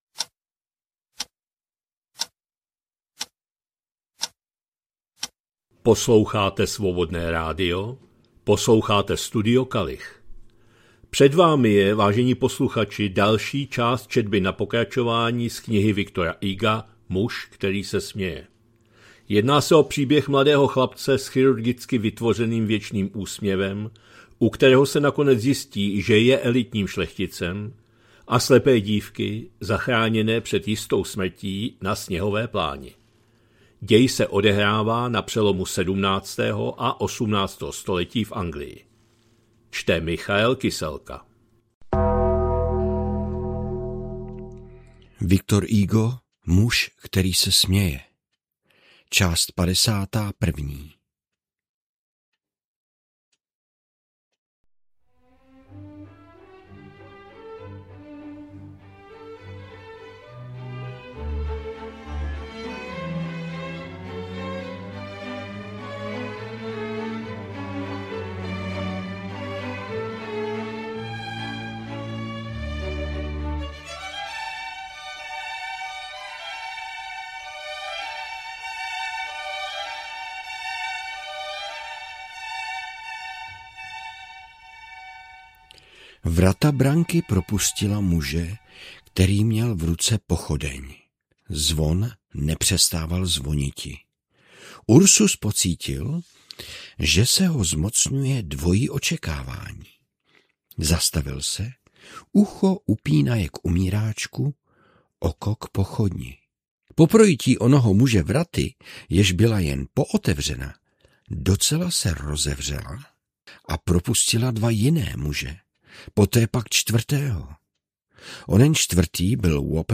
2026-03-06 - Studio Kalich - Muž který se směje, V. Hugo, část 51., četba na pokračování